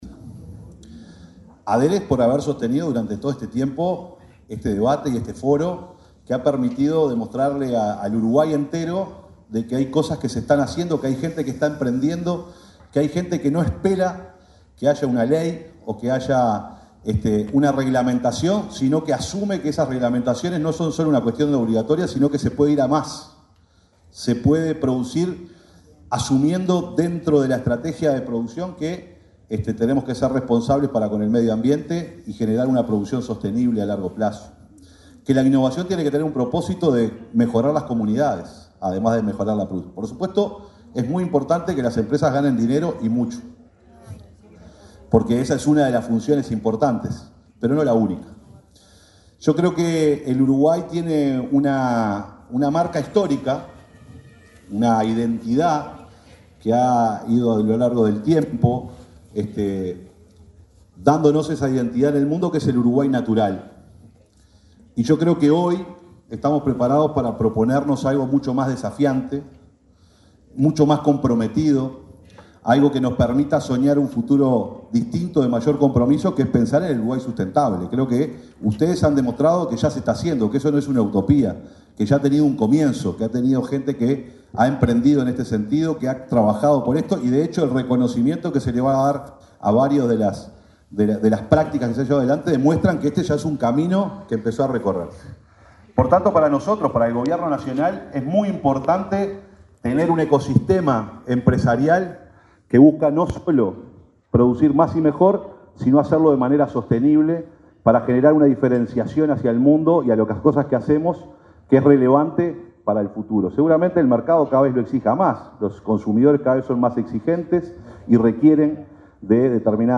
Palabras del secretario de Presidencia, Alejandro Sánchez
El evento se desarrolló este lunes 17 en la Torre Ejecutiva.